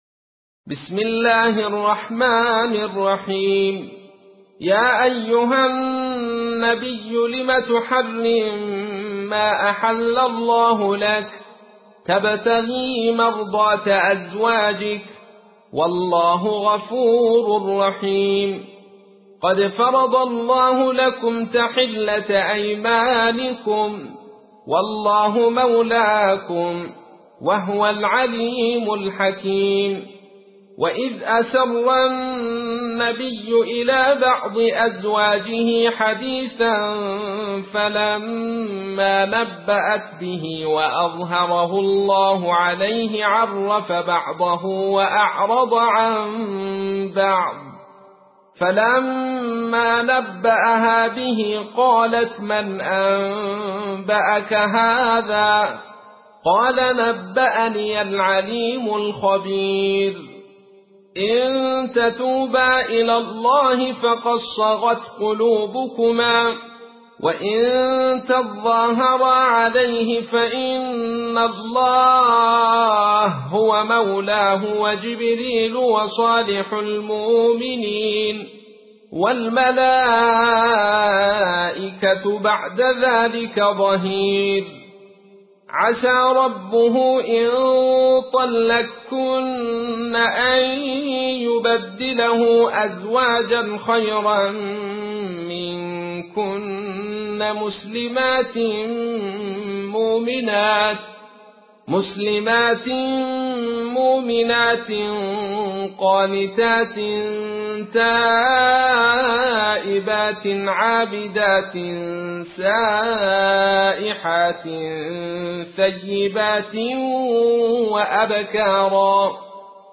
تحميل : 66. سورة التحريم / القارئ عبد الرشيد صوفي / القرآن الكريم / موقع يا حسين